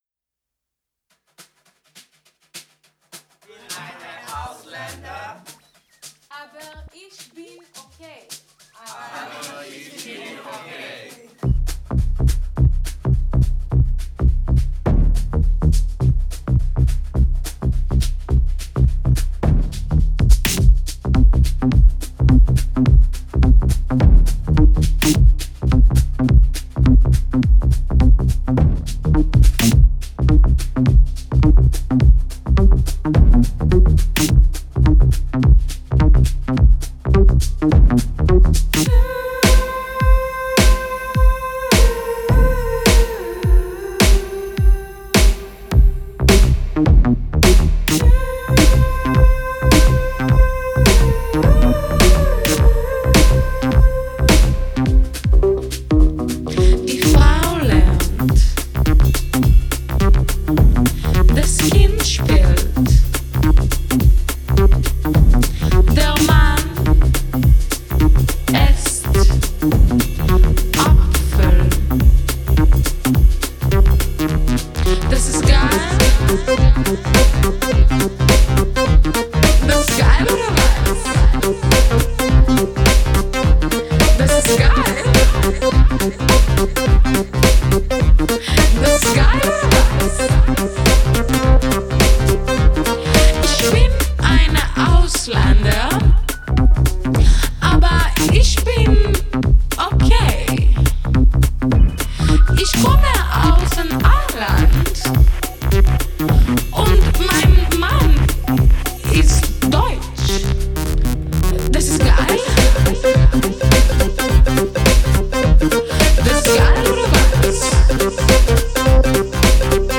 Genre: Pop, Pop Rock, Synth